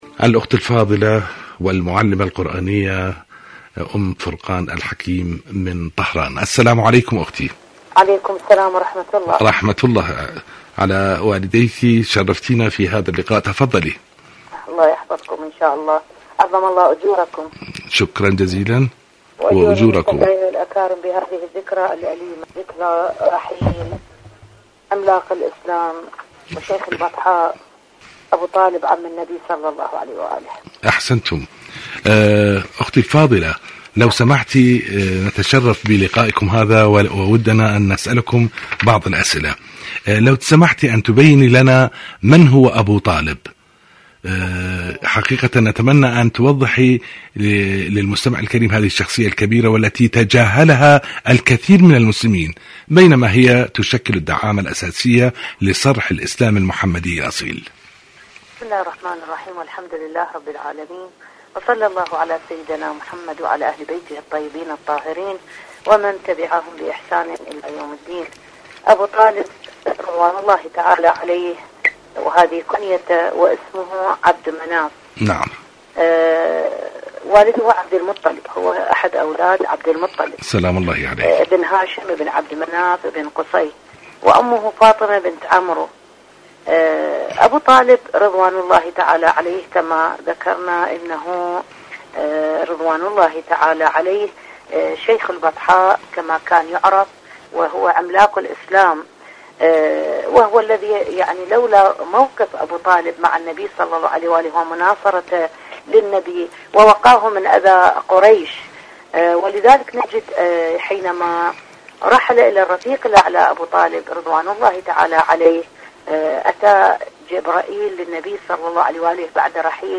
إذاعة طهران العربية مقابلات إذاعية برنامج المؤمن المظلوم